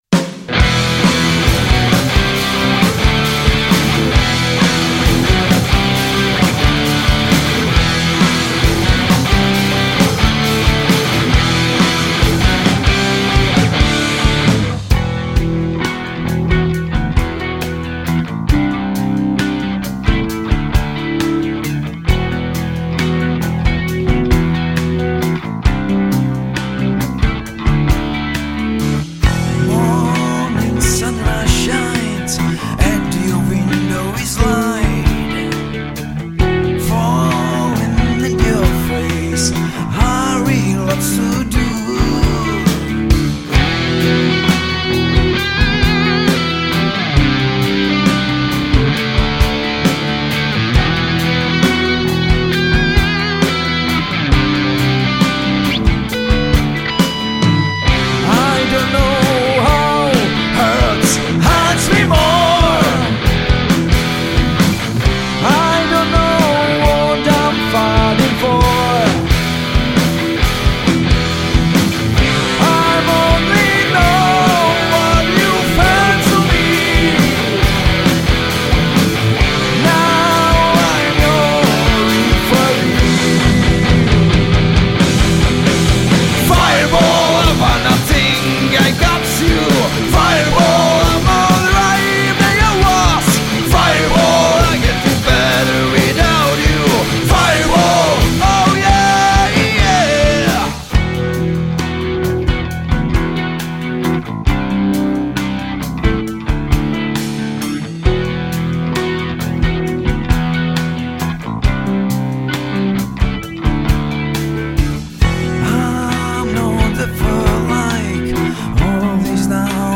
Žánr: Rock
Hard-rock 70-90.let s moderním soundem a aranžemi